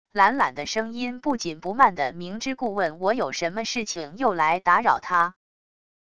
懒懒的声音不紧不慢的明知故问我有什么事情又来打扰他wav音频